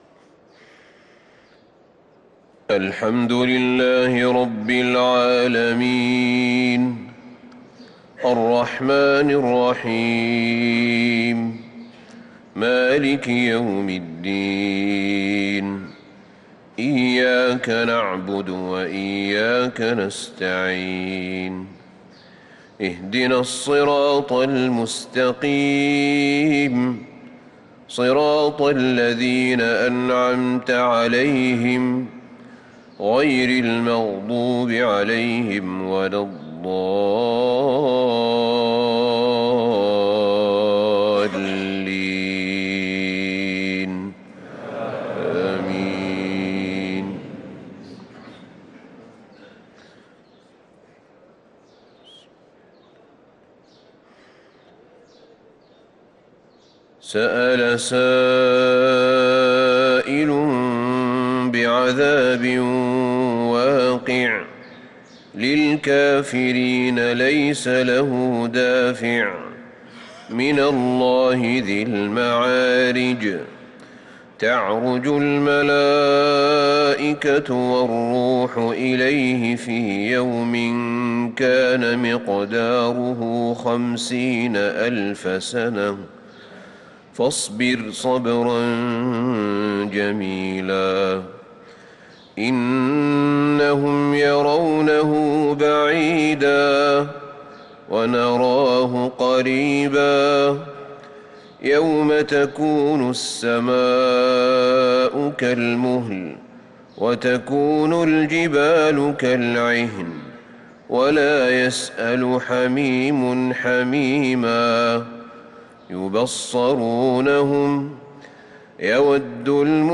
صلاة الفجر للقارئ أحمد بن طالب حميد 28 جمادي الآخر 1445 هـ
تِلَاوَات الْحَرَمَيْن .